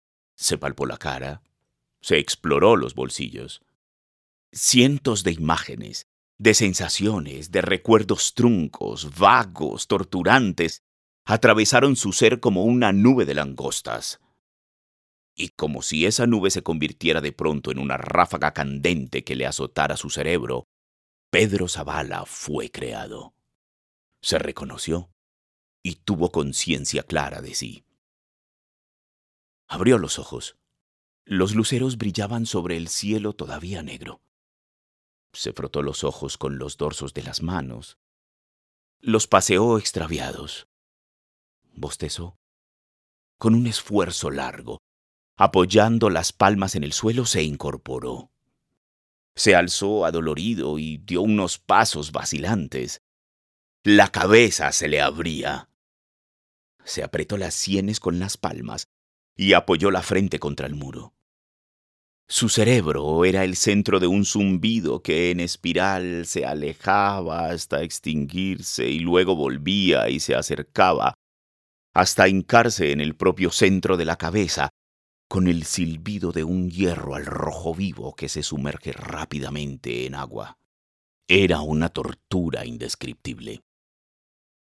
Producción de Audiolibros